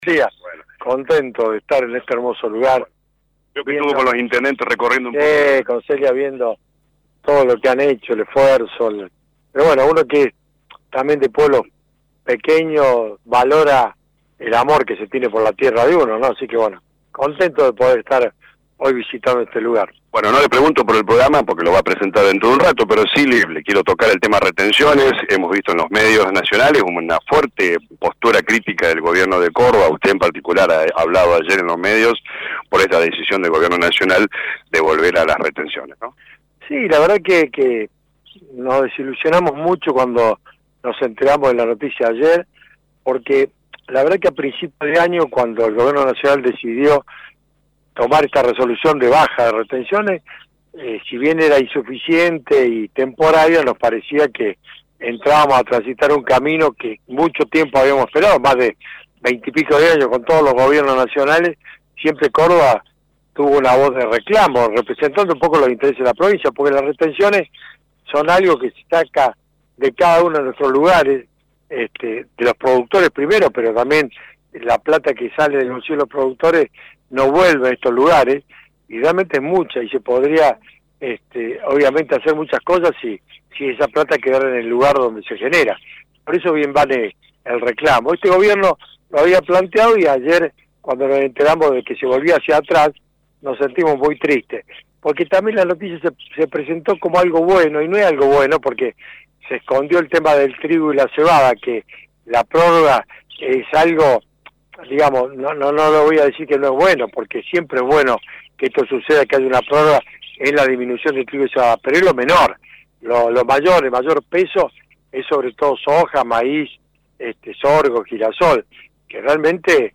El ministro de Bioagroindustria de Córdoba, Sergio Busso, expresó en diálogo con LA RADIO 102.9 su rechazo a la decisión del Gobierno Nacional de revertir la reducción de retenciones anunciada en enero y restablecer, a partir de julio, las alícuotas anteriores para productos como la soja, el maíz, el girasol y el sorgo.